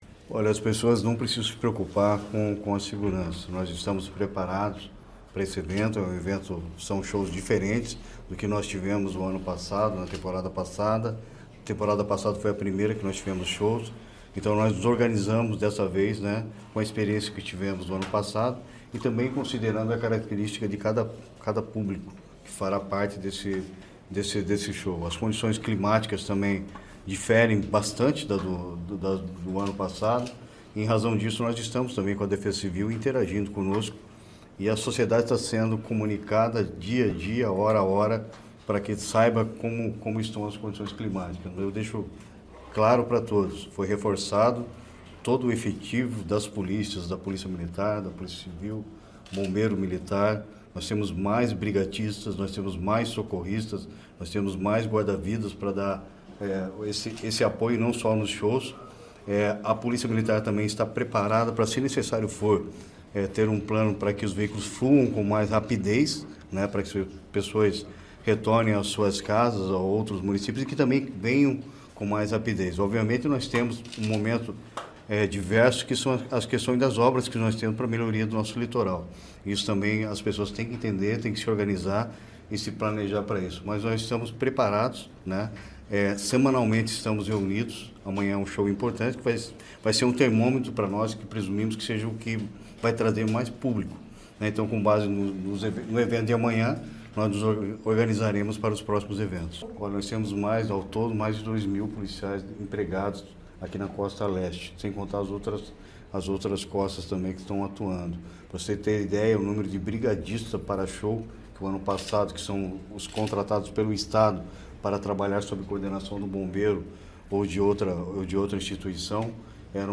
Sonora do secretário da Segurança Pública, Hudson Leôncio Teixeira, sobre o reforço no policiamento para os shows do Verão Maior